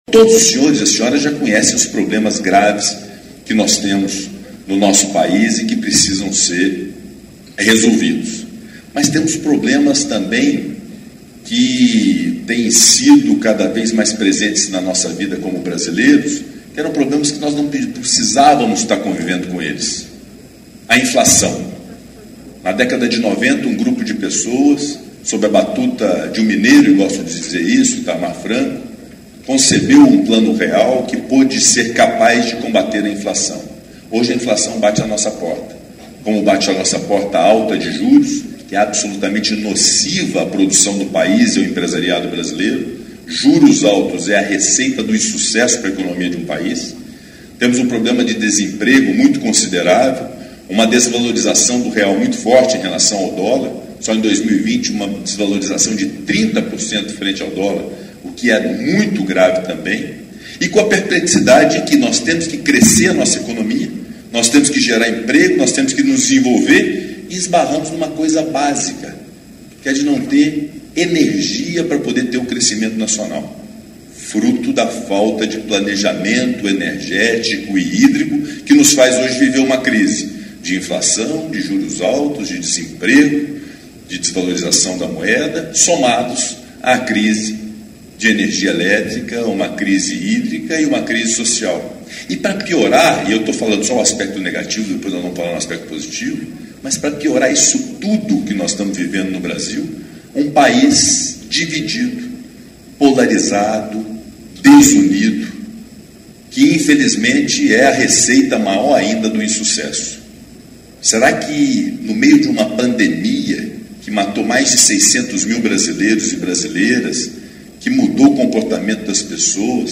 Player Ouça rodrigo Pacheco, presidente do Senado